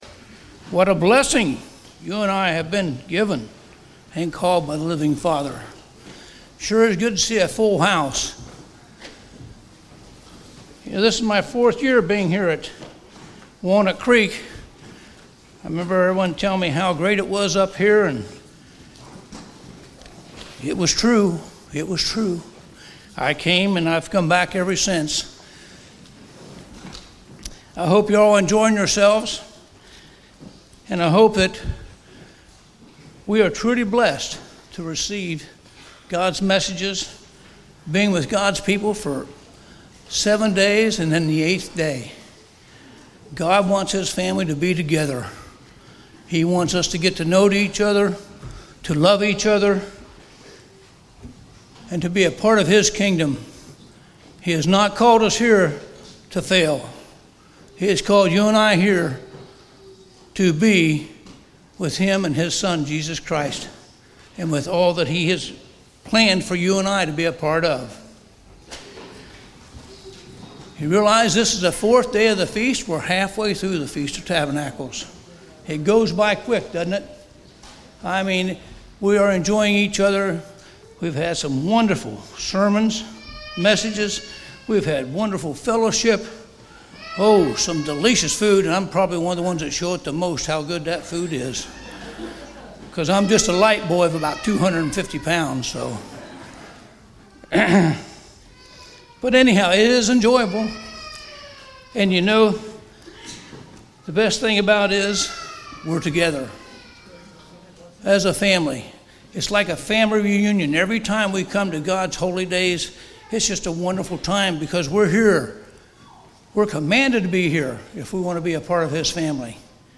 Sermons
Given in Walnut Creek, Ohio